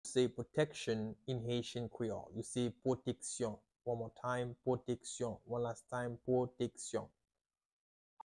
How to say "Protection" in Haitian Creole - "Pwoteksyon" pronunciation by a native Haitian Teacher
“Pwoteksyon” Pronunciation in Haitian Creole by a native Haitian can be heard in the audio here or in the video below:
How-to-say-Protection-in-Haitian-Creole-Pwoteksyon-pronunciation-by-a-native-Haitian-Teacher.mp3